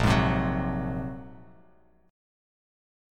Bb7sus2#5 chord